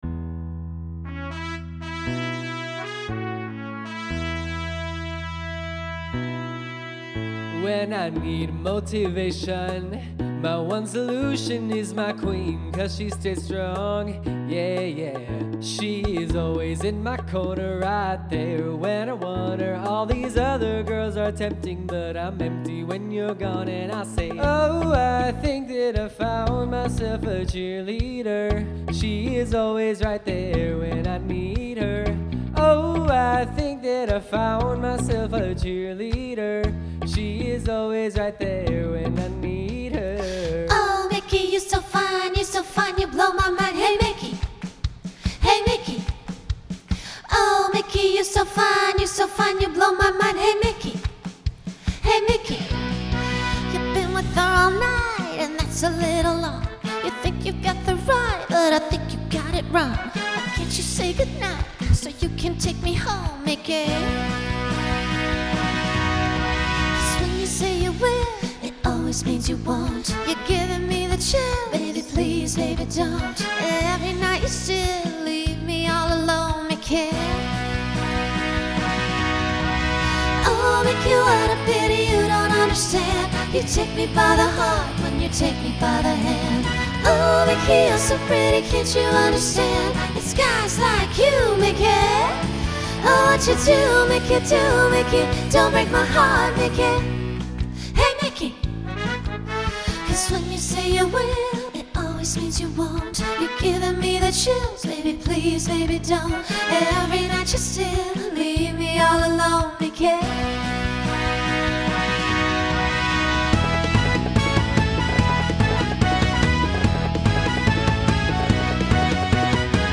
Starts with a male solo.
Pop/Dance , Rock
Transition Voicing SSA